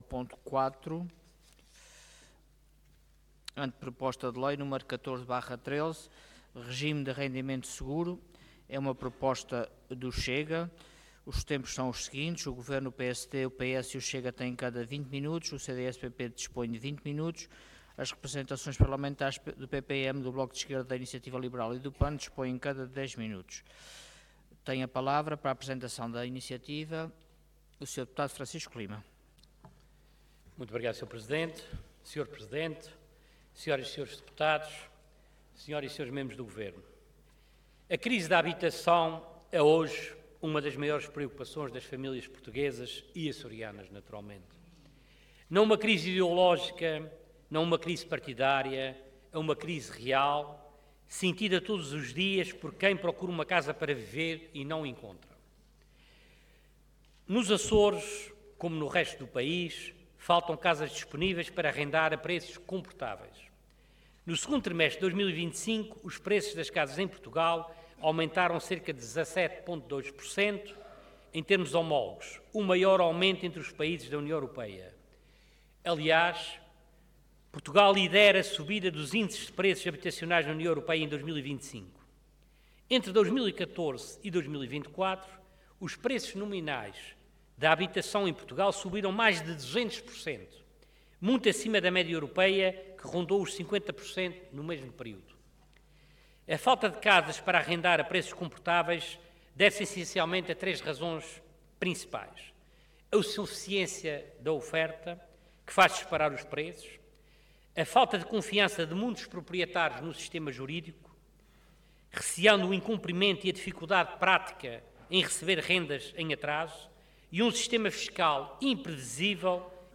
Detalhe de vídeo 13 de janeiro de 2026 Download áudio Download vídeo Processo XIII Legislatura Regime de Arrendamento Seguro Intervenção Anteproposta de Lei Orador Francisco Lima Cargo Deputado Entidade CH